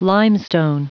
Prononciation du mot limestone en anglais (fichier audio)
Prononciation du mot : limestone